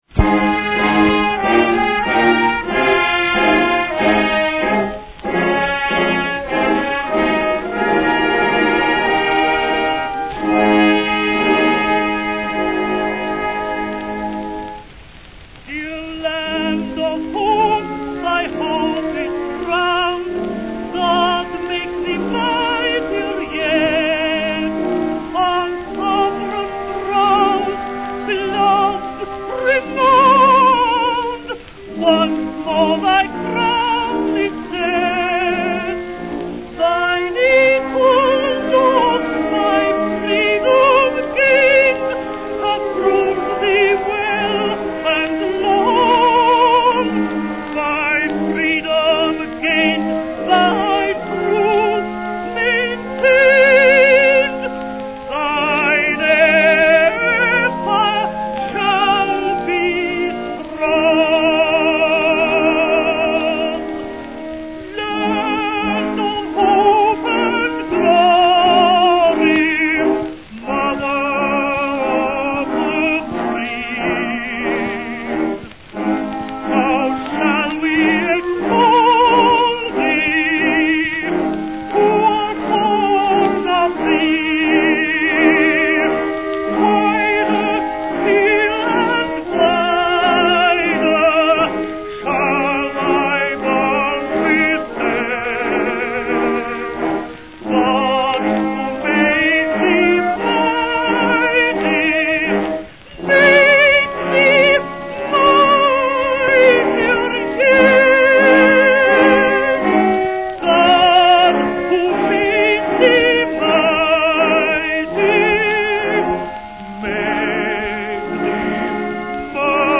Click this line to hear Dame Clara Butt singing an original version in 1911 (mp3 484Kb)
We just love the different pace and phraseology of this version of "Land of Hope and Glory".
Do please listen, and yes we appreciate that the orchestra sounds like a group of musicians dragged in after a long session in The Green Man, but still an historic recording now well over 100 years old!